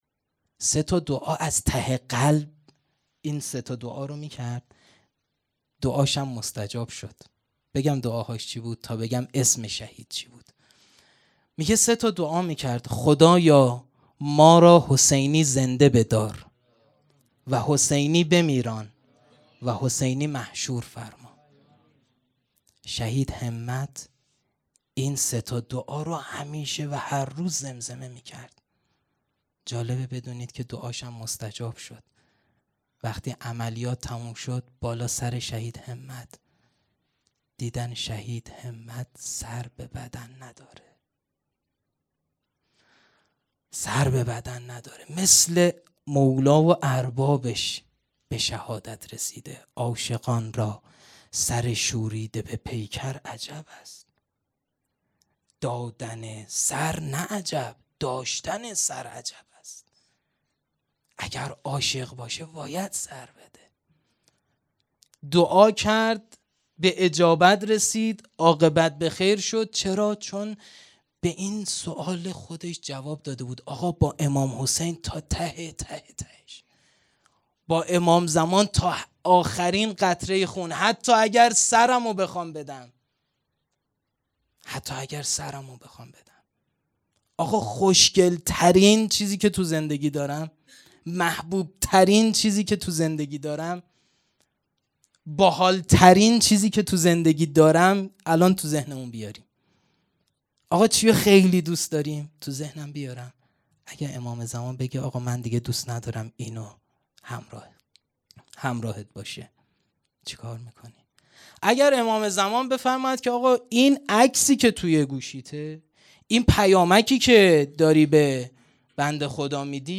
سخنرانی
محرم 1440 _ شب هشتم